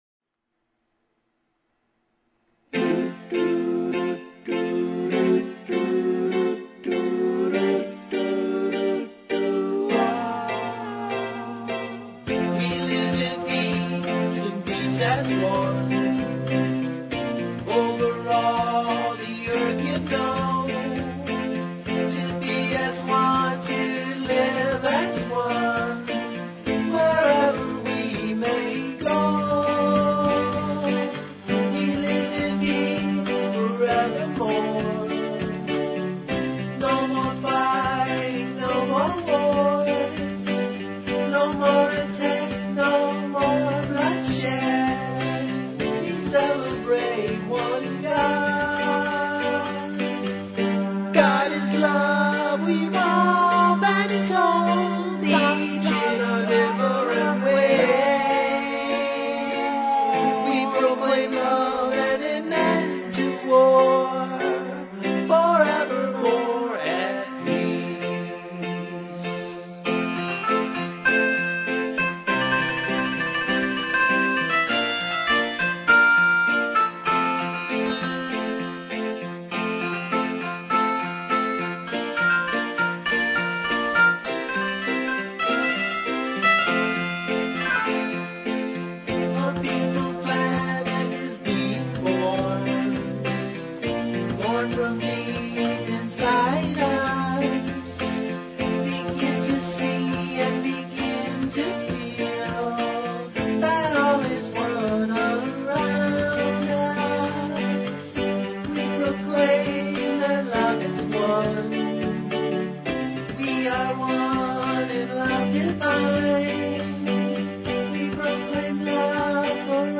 Talk Show Episode, Audio Podcast, Peaceful_Planet and Courtesy of BBS Radio on , show guests , about , categorized as
Your calls for readings are also welcomed!